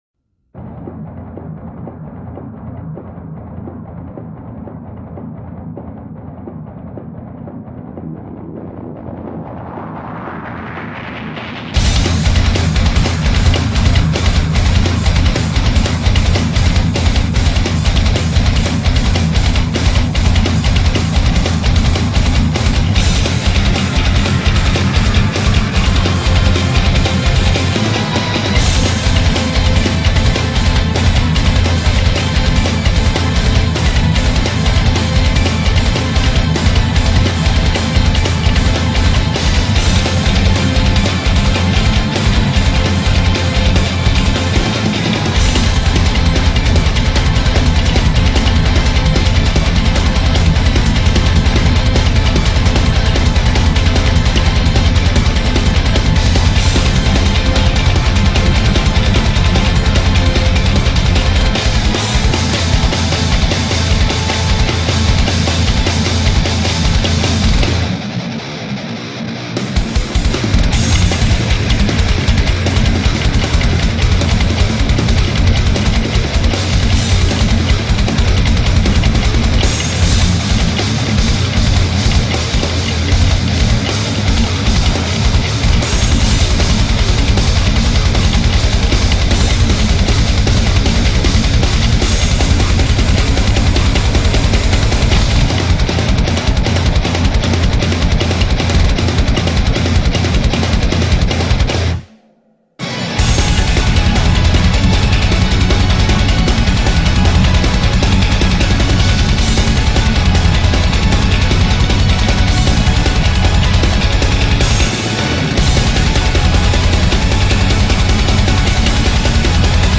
BPM155
Audio QualityPerfect (High Quality)
Metal woo!